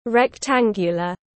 Hình hộp chữ nhật tiếng anh gọi là rectangular, phiên âm tiếng anh đọc là /rekˈtæŋ.ɡjə.lər/.
Rectangular /rekˈtæŋ.ɡjə.lər/
Rectangular.mp3